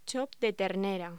Locución: Chop de ternera
voz